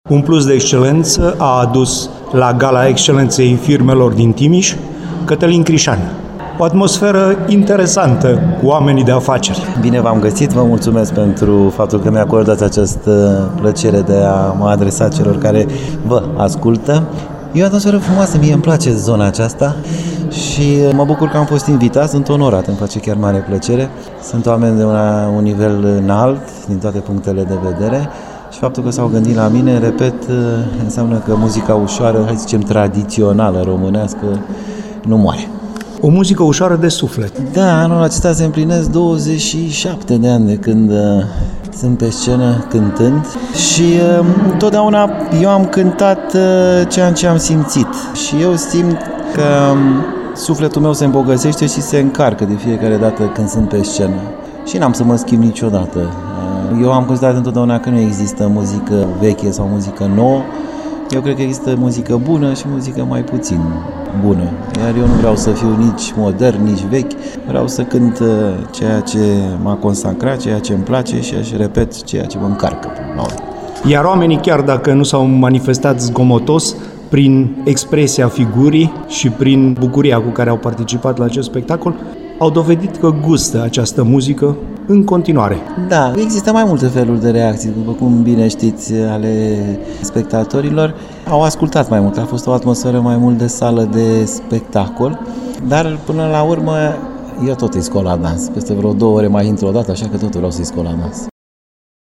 Interviuri